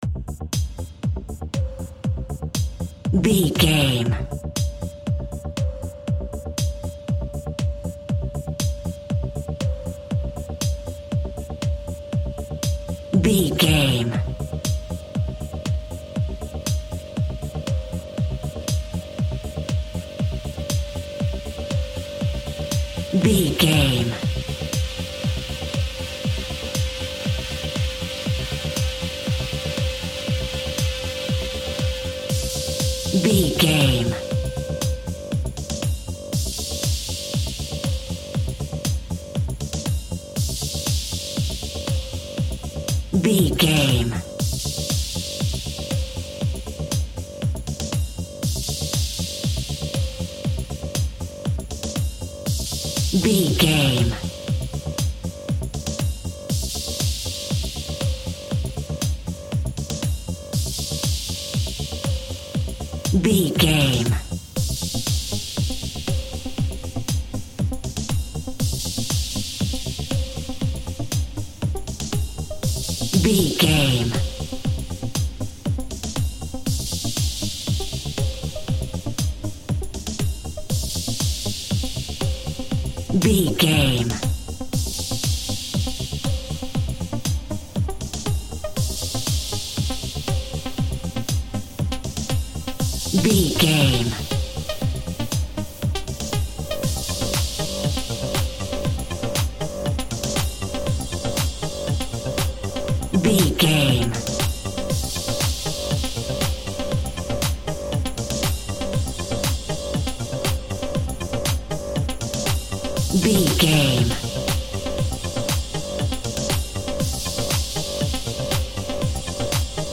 Modern Pop Top 40 Electronic Dance Music Alt.
Epic / Action
Fast paced
Aeolian/Minor
dark
futuristic
groovy
aggressive
repetitive
synthesiser
drum machine
house
techno
synth leads
synth bass
upbeat